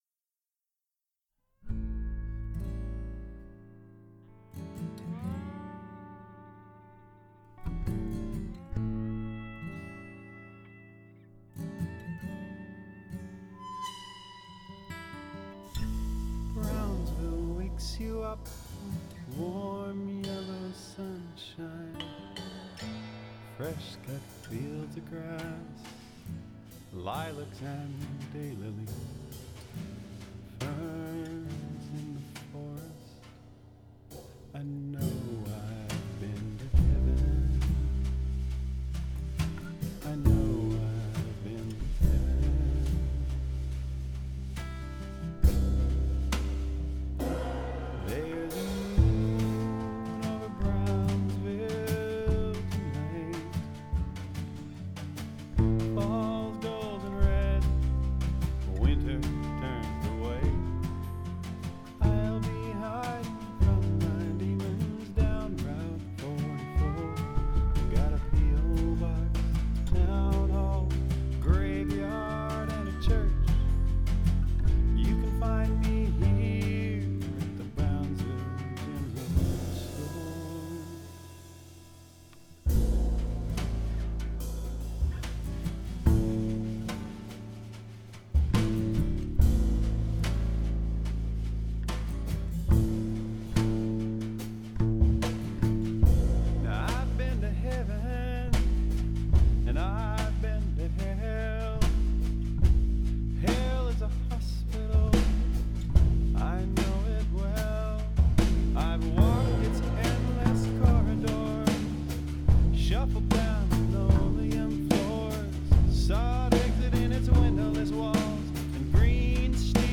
brownsville-bass-n-drums.mp3